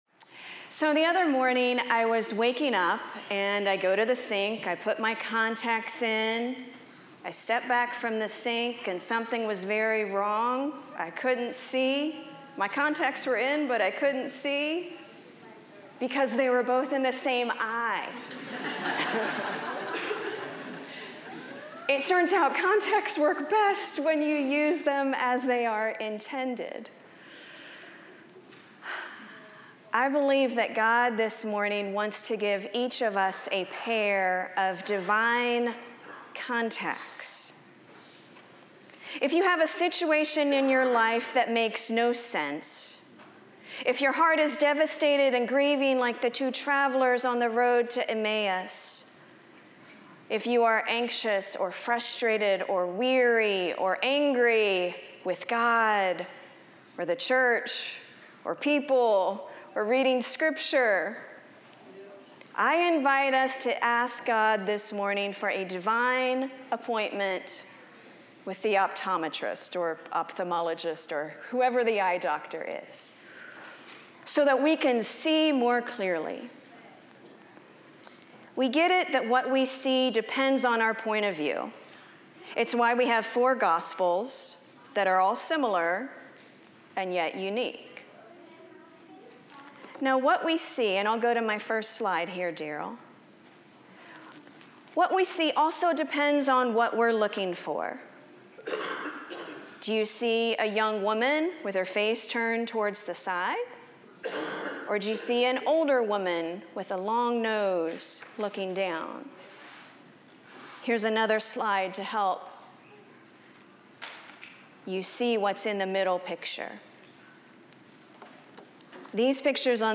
- Isaiah 55:1-13 Order of worship/bulletin Youtube video recording Sermon audio recording.